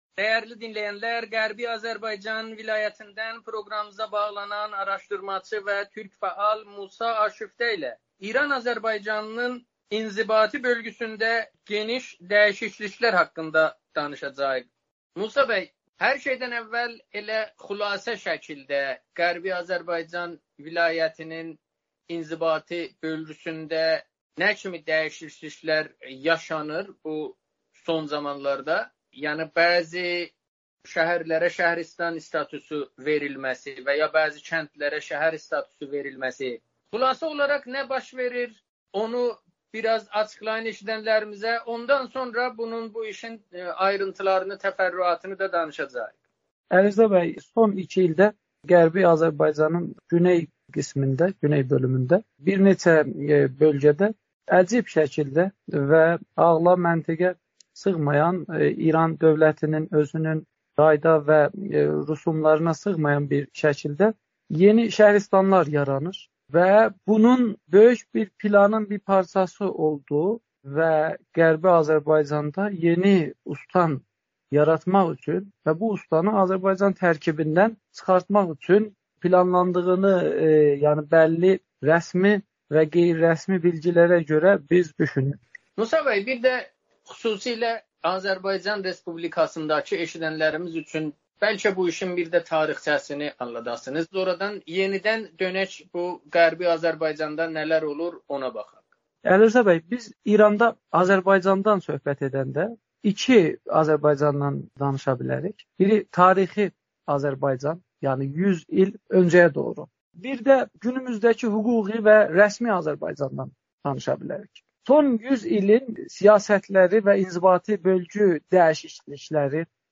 müsahibədə